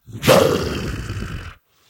Здесь вы найдете реалистичные аудиозаписи воя, рычания и других эффектов, связанных с этими легендарными существами.
еще активный рык